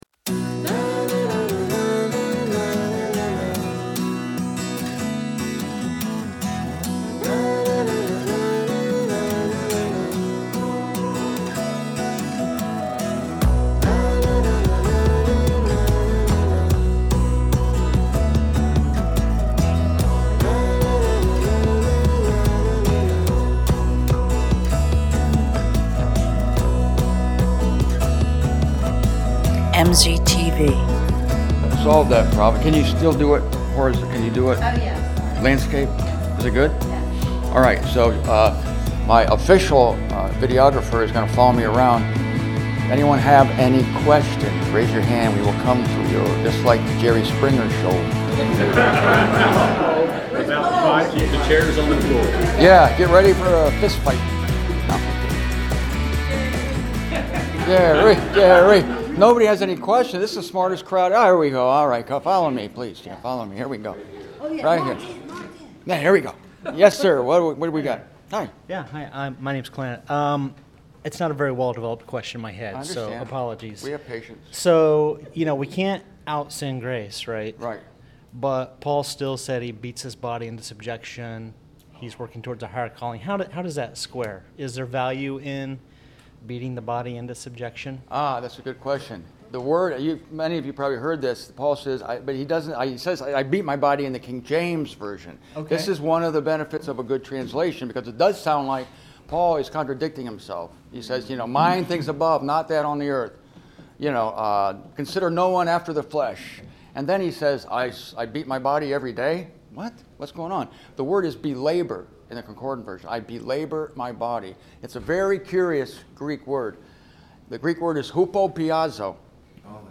At the Columbia, SC conference last month